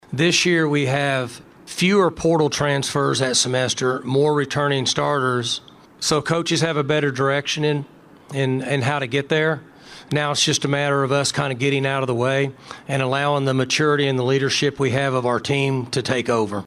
OK-State at Big 12 Media Days
Gundy talks about this Oklahoma State team’s experience.